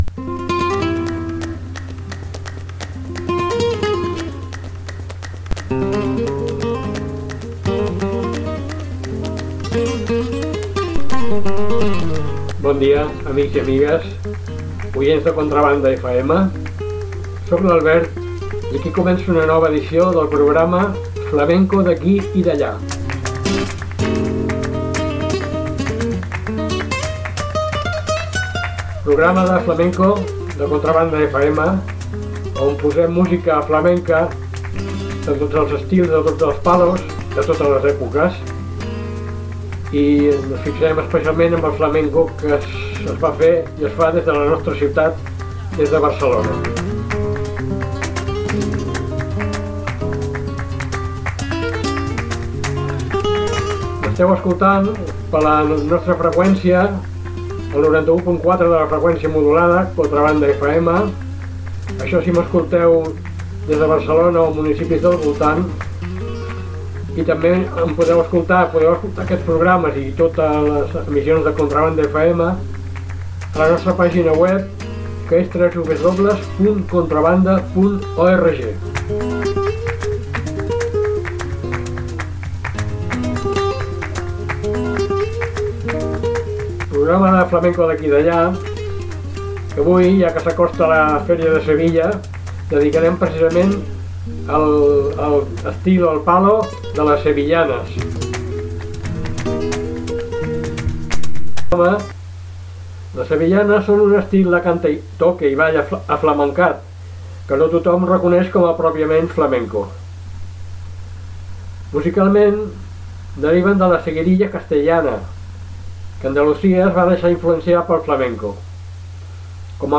Les sevillanas, folklore aflamencat.